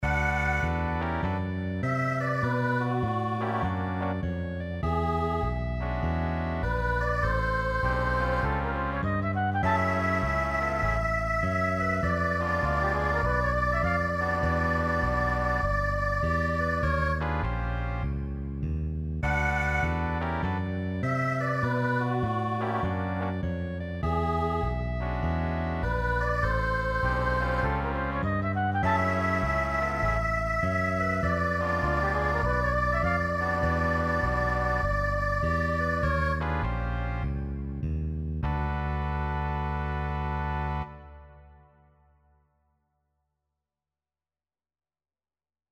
4.3-  Arrangement avec section de cuivres (cours) + (vidéo) + (ex. 1 à 6) + (corrigé des ex. 4 et 6) + (mp3 de l'ex. 4) + (
Avec section de cuivres corrige 7.mp3